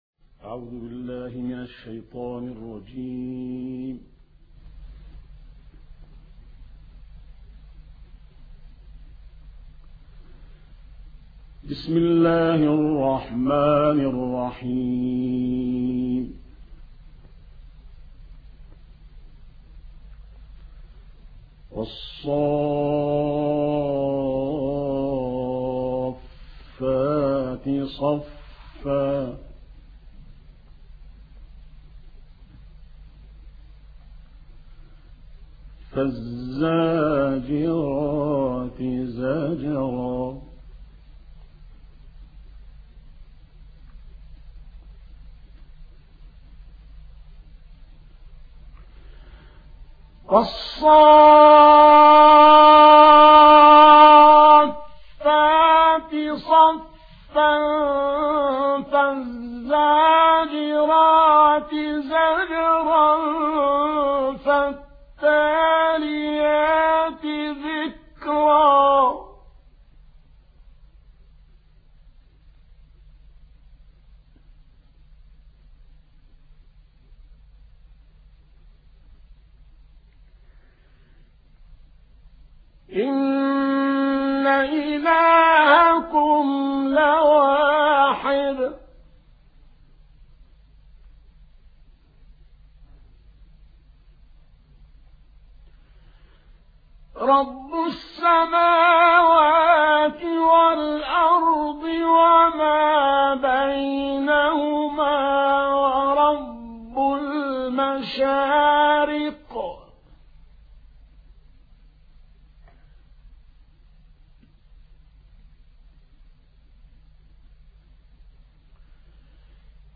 ما تيسر من سورة الصافات القارئ الشيخ عبد العظيم زاهر